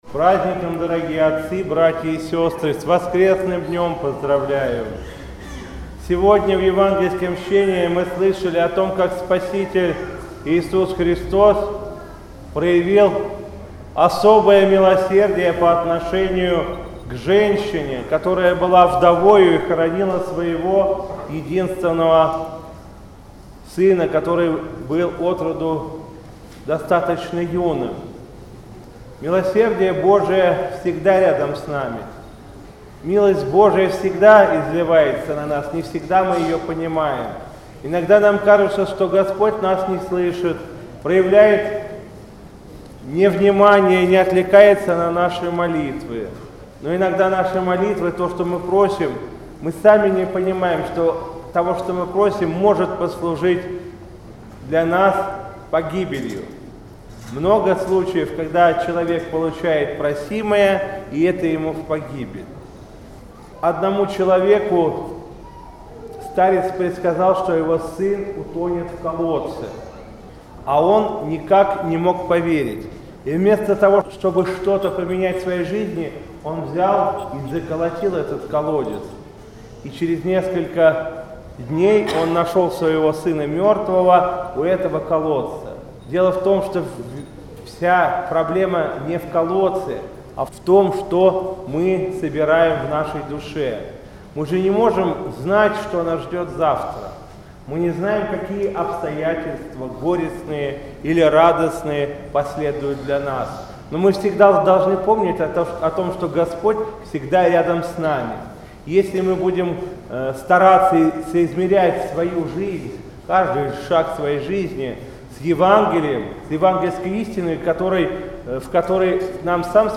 По окончании богослужения владыка Игнатий поздравил присутствующих с воскресным днём и обратился с архипастырским словом на тему воскресного Евангельского чтения о воскрешении сына Наинской вдовы.